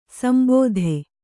♪ sambōdhe